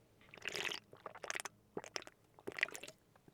Drinking Ice Tea Sound Effect Free Download
Drinking Ice Tea